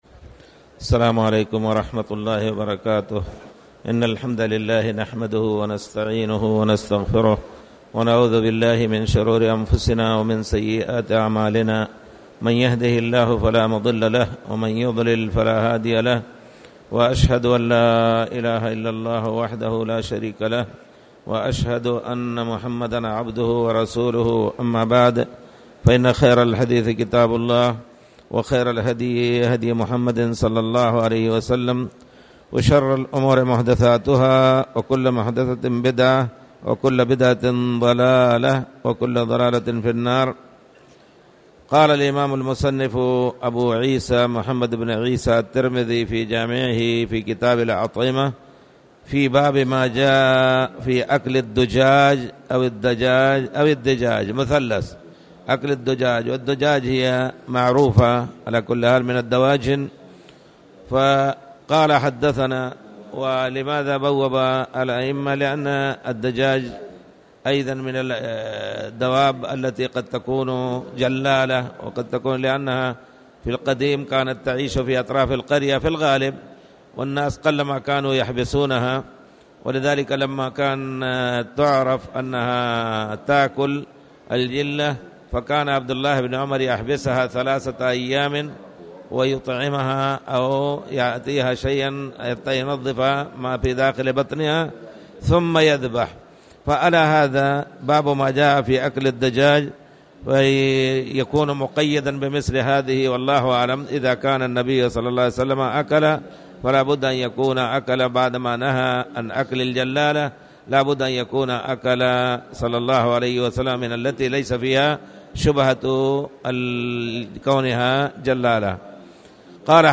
تاريخ النشر ١٥ رمضان ١٤٣٨ هـ المكان: المسجد الحرام الشيخ